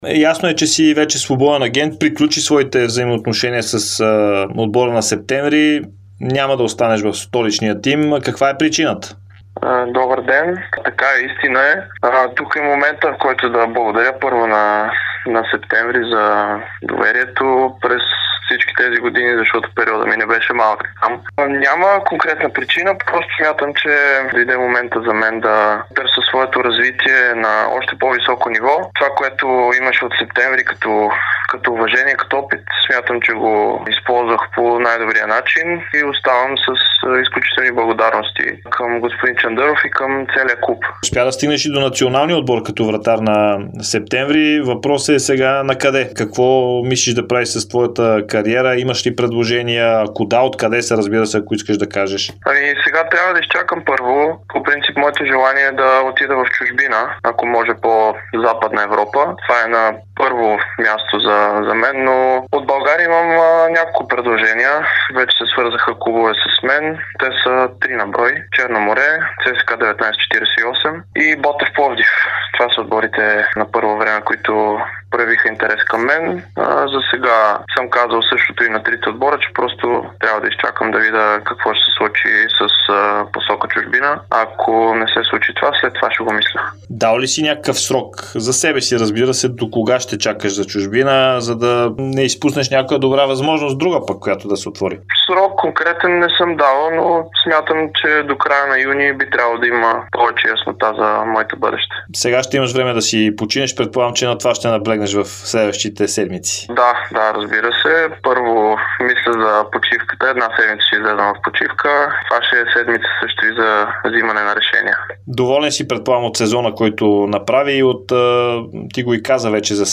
Националният вратар Димитър Шейтанов няма да продължи кариерата си в Септември, след като договорът му изтече. Той даде специално интервю за Дарик радио и dsport, в което разкри от кои отбори е получил предложение, но заяви, че първо гледа към чужбина.